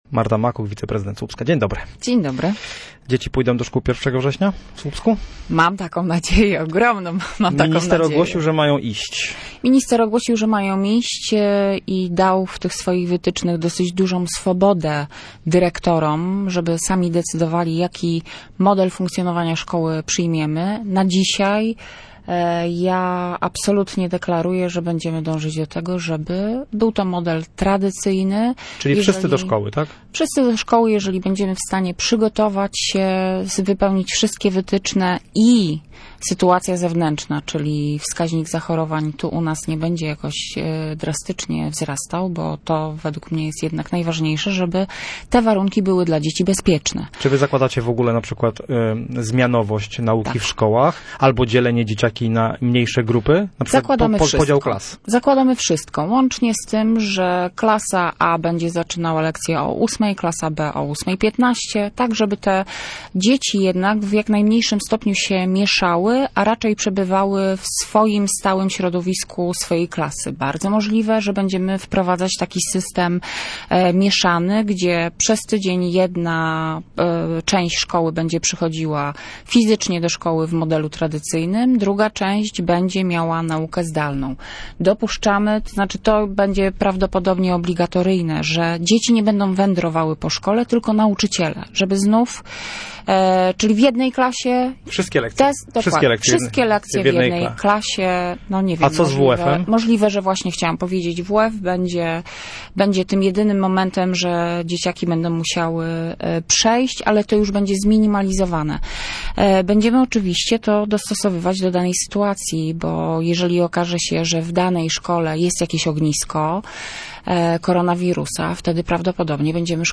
Marta Makuch, wiceprezydent Słupska była gościem miejskiego programu Radia Gdańsk Studio Słupsk 102 FM.